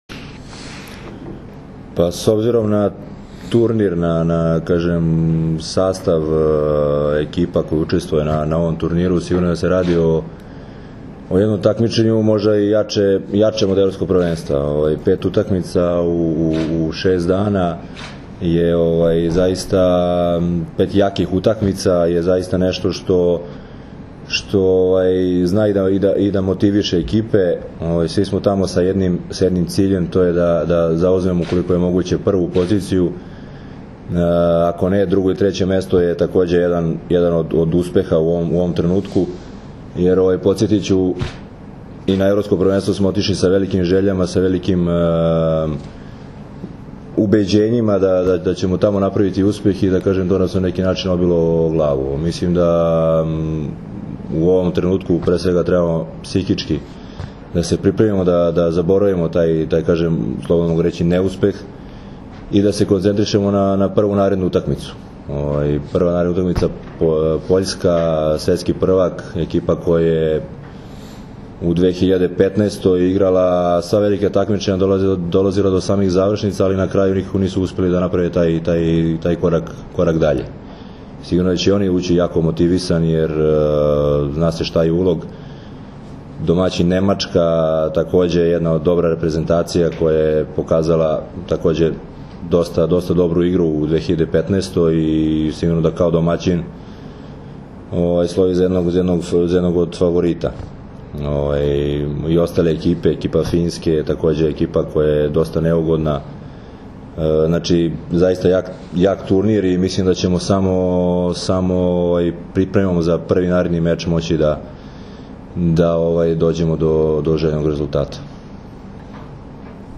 Tim povodom danas je u beogradskom hotelu “M” održana konferencija za novinare, kojoj su prisustvovali Nikola Grbić, Dragan Stanković, Marko Ivović i Uroš Kovačević.
IZJAVA DRAGANA STANKOVIĆA